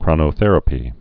(krŏnō-thĕrə-pē, krōnə-)